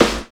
62 SNARE.wav